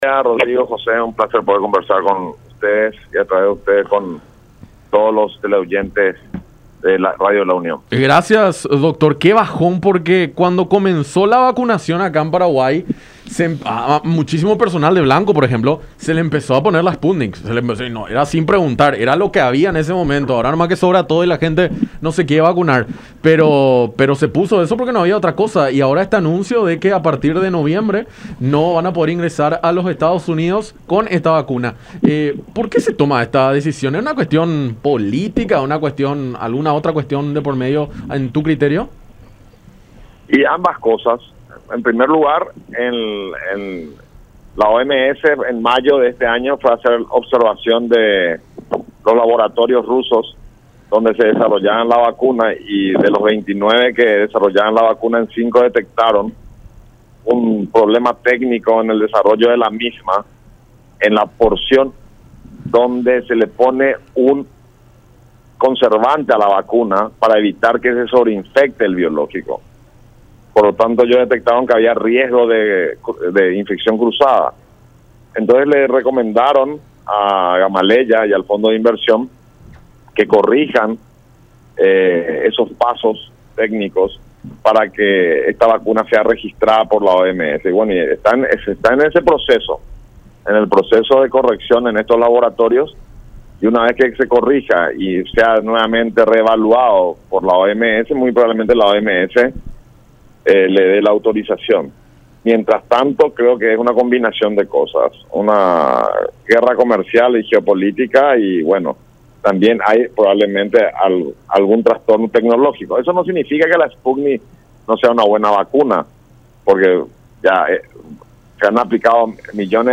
en conversación con Enfoque 800 a través de La Unión.